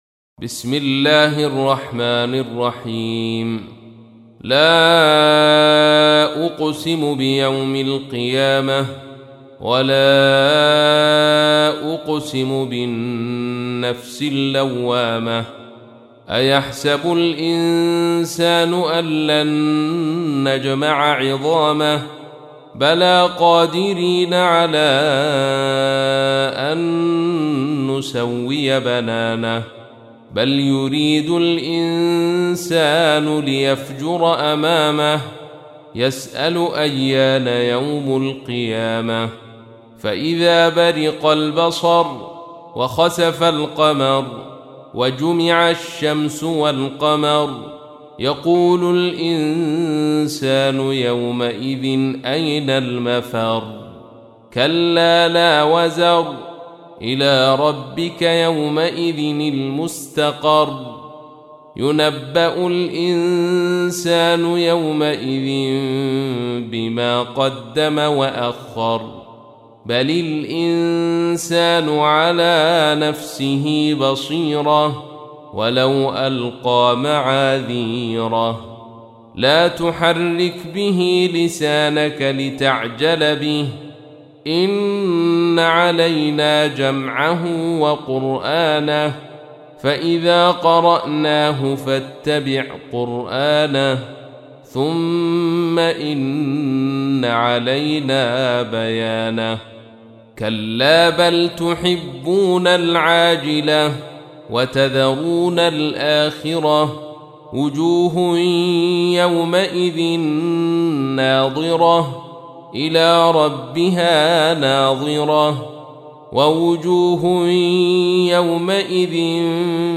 75. سورة القيامة / القارئ